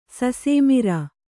♪ sasēmirā